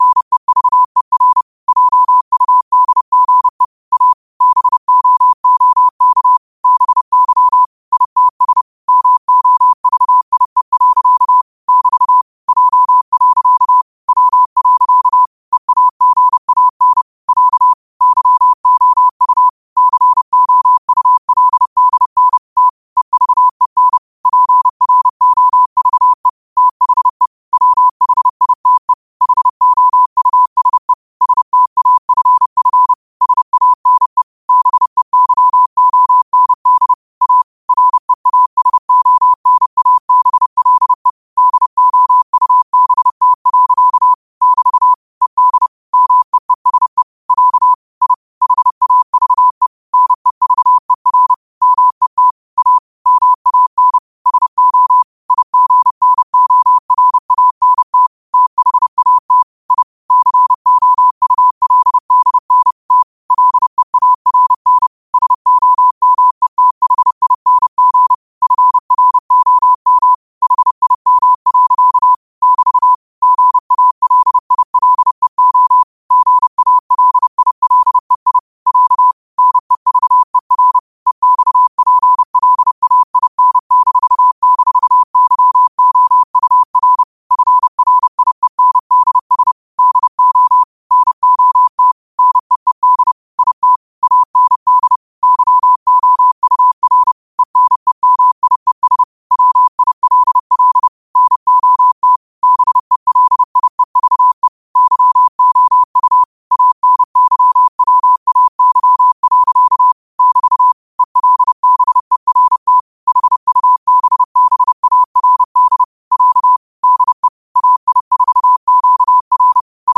New quotes every day in morse code at 30 Words per minute.